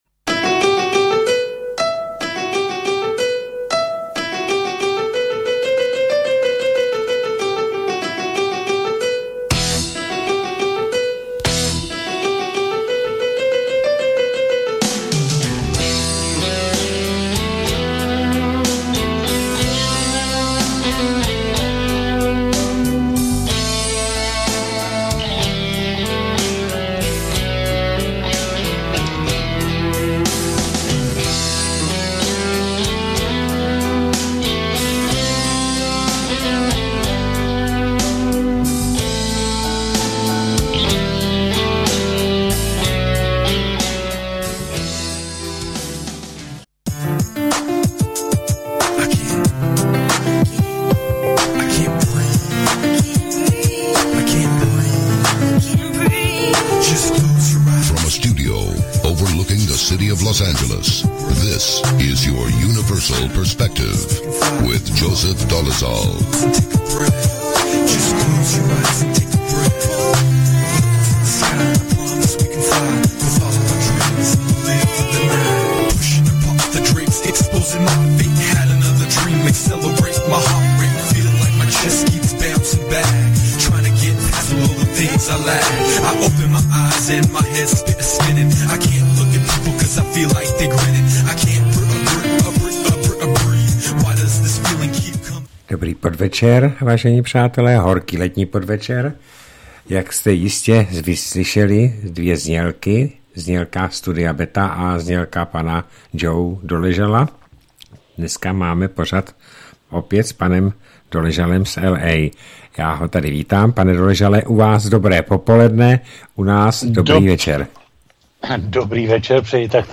pořad přerušen několika výpadky internetu.